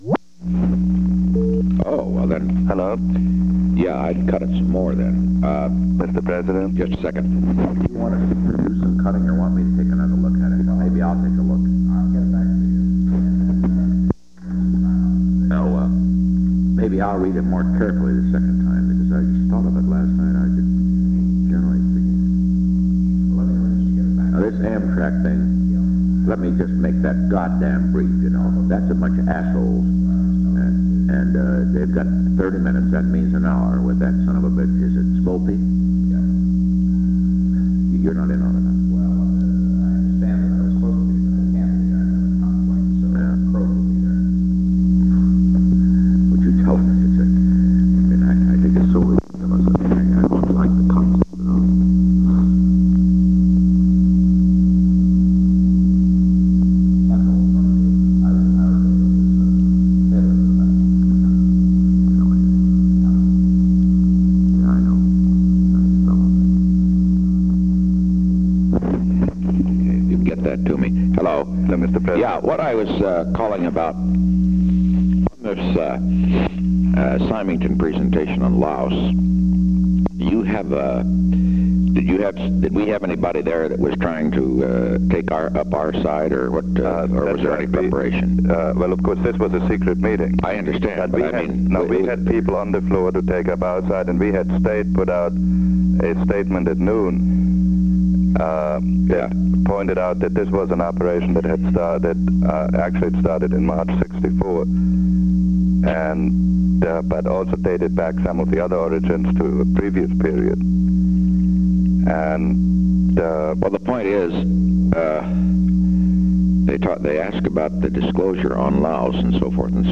Location: White House Telephone
Henry A. Kissinger talked with the President.